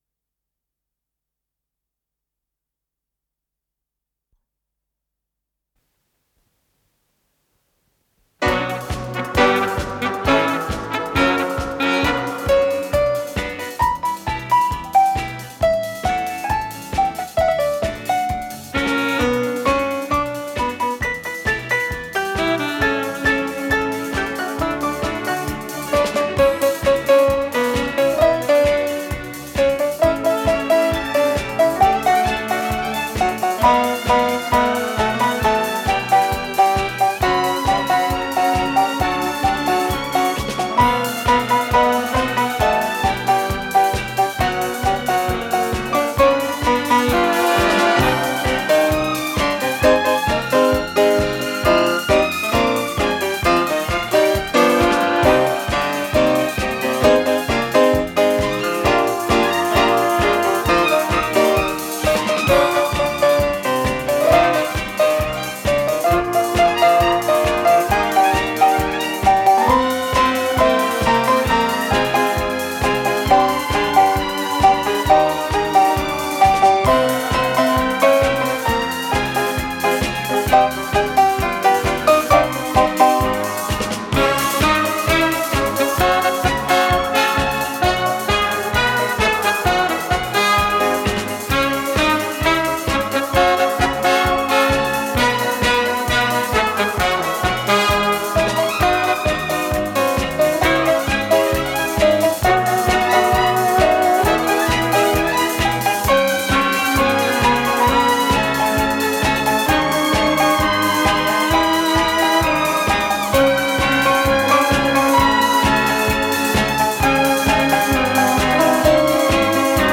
с профессиональной магнитной ленты
ПодзаголовокЗаставка
ВариантДубль моно